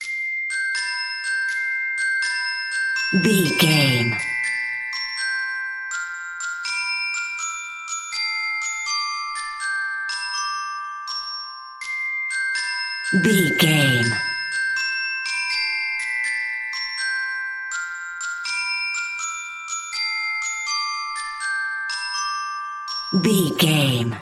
Ionian/Major
Slow
nursery rhymes
childrens music